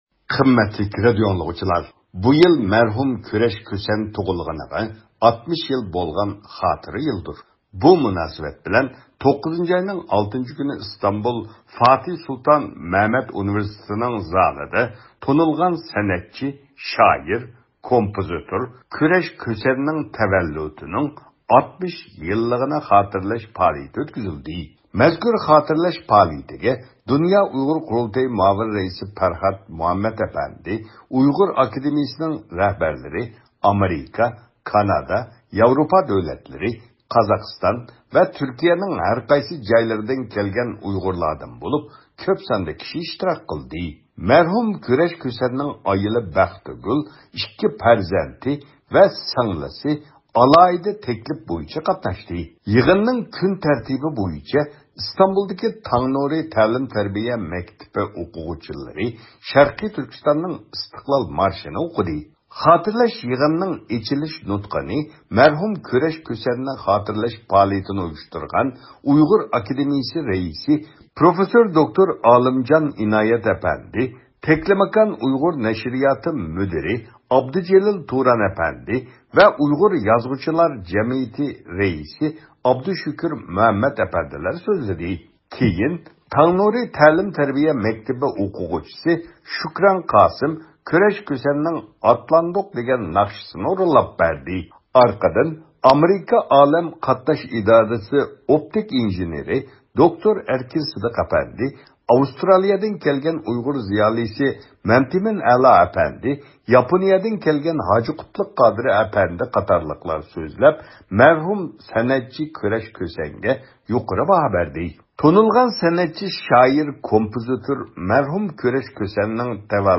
ئىختىيارىي مۇخبىرىمىز